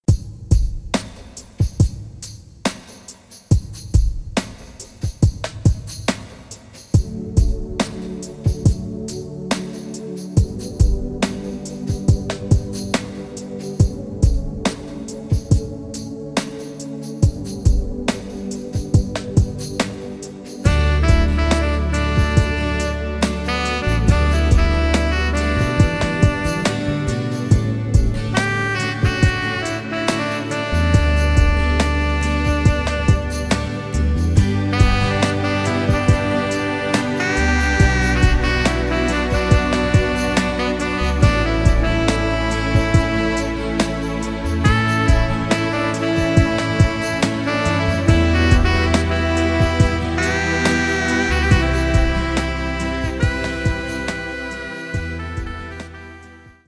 Gefühlvoll arrangierte Instrumentalmusik zum Planschen …